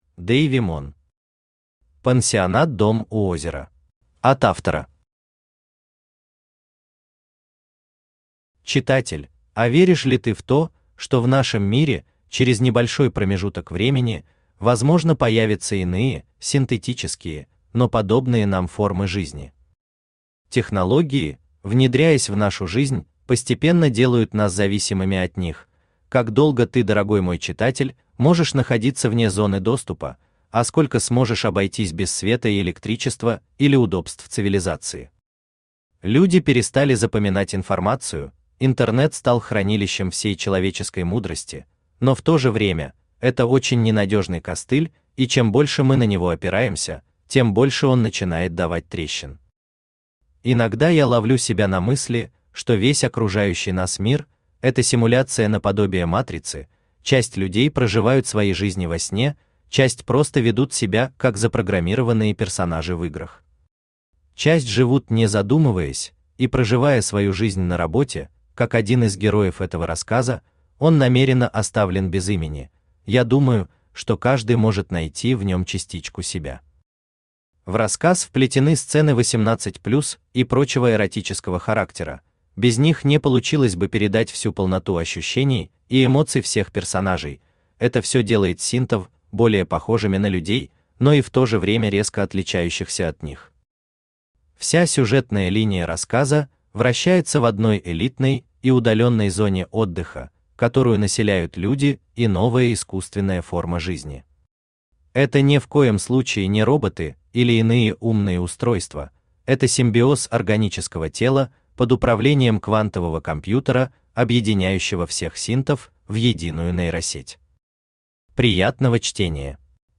Аудиокнига Пансионат «Дом у озера» | Библиотека аудиокниг
Aудиокнига Пансионат «Дом у озера» Автор Дейви Мон Читает аудиокнигу Авточтец ЛитРес.